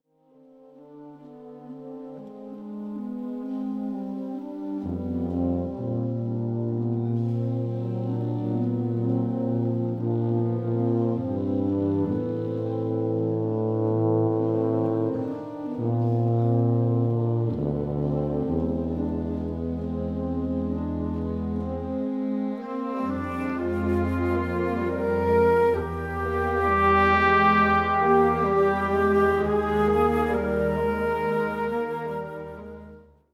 This new concert band studio recording